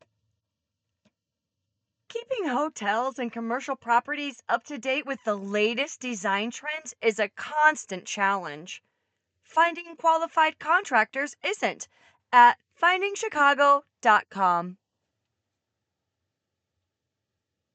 Professional-grade recording equipment and acoustically treated space